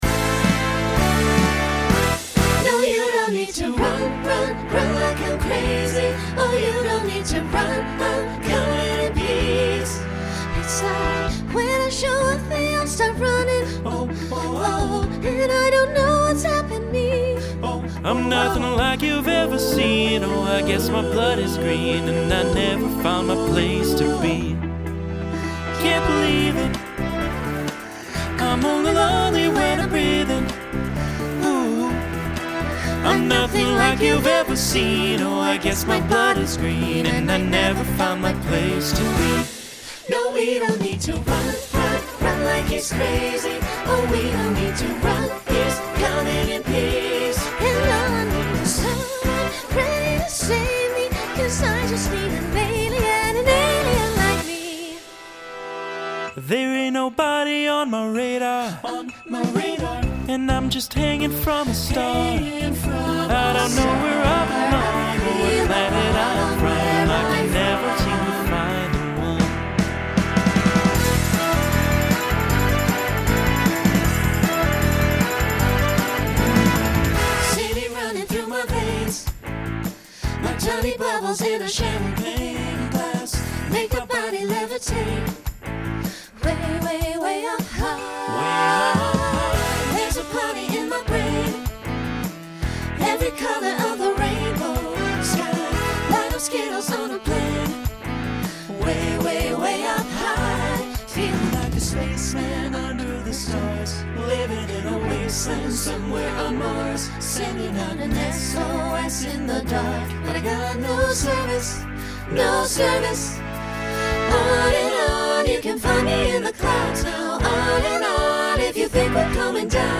Genre Pop/Dance , Rock
Story/Theme Voicing SATB